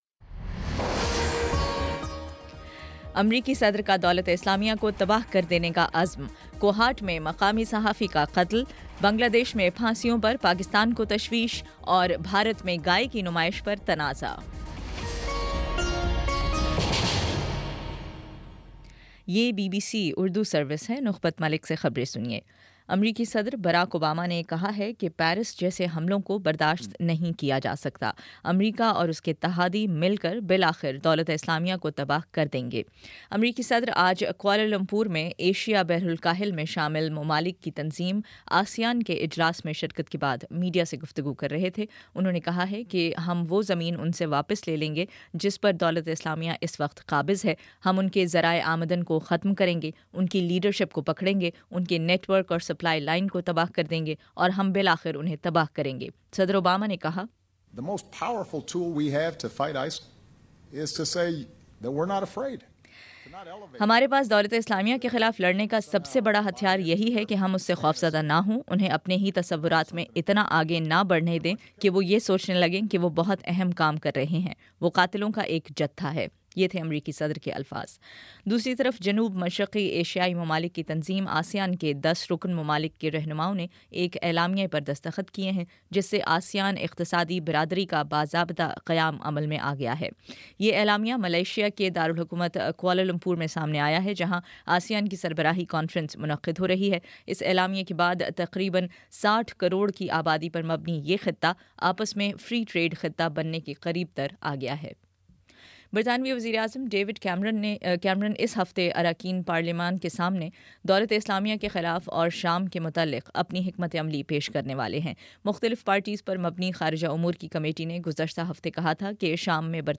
نومبر 22 : شام پانچ بجے کا نیوز بُلیٹن